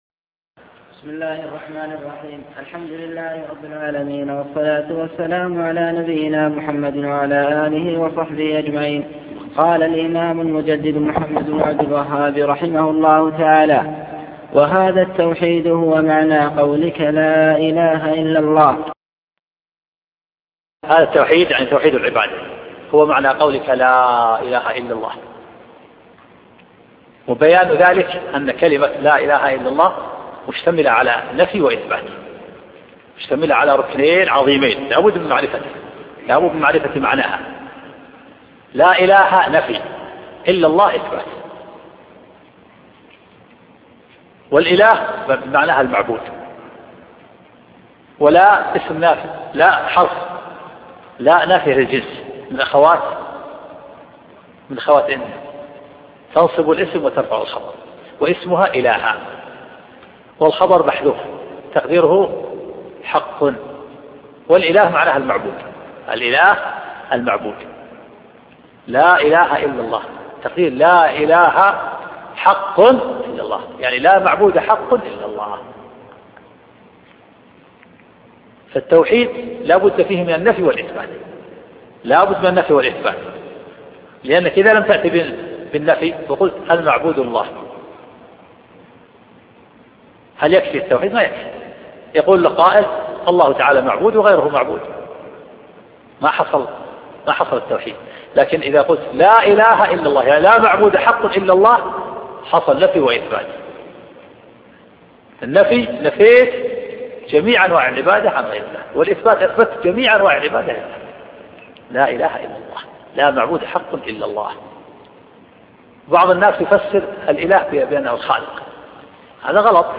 كشف الشبهات شرح الشيخ عبد العزيز بن عبد الله الراجحي الدرس 24